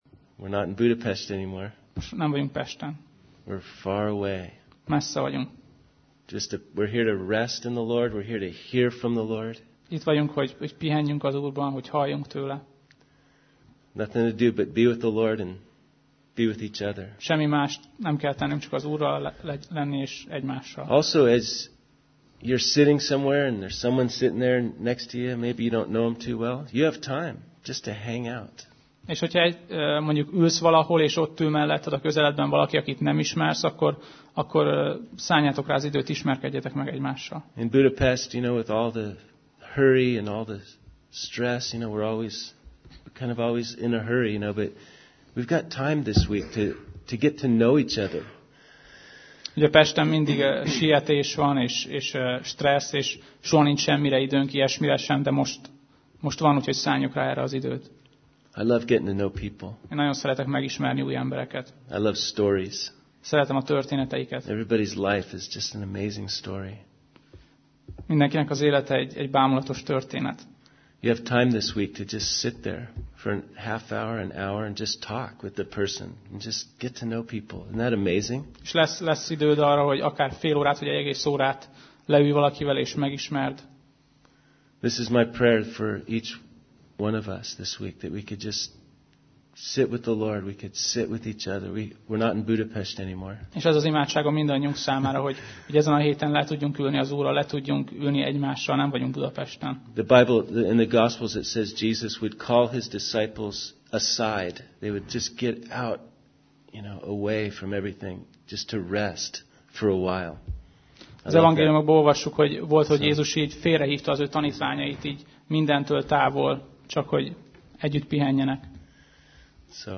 Sorozat: Vajta Konferencia 2005 Alkalom: Konferencia